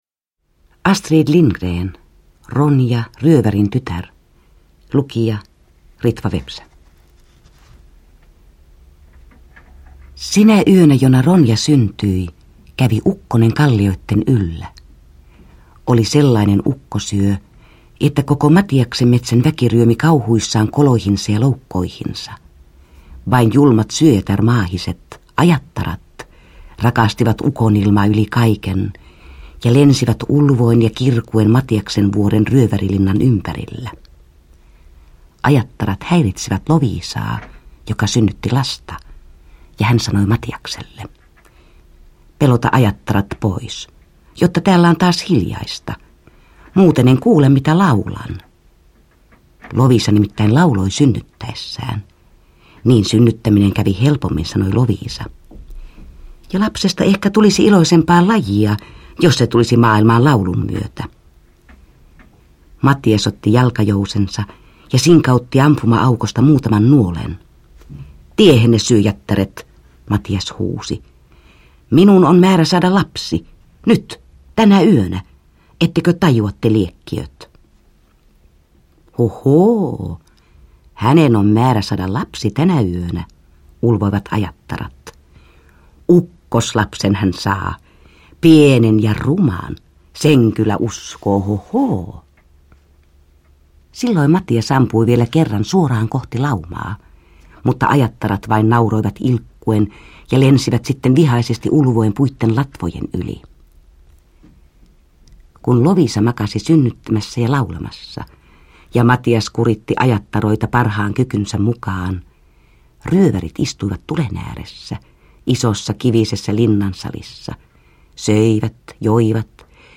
Ronja, ryövärintytär – Ljudbok – Laddas ner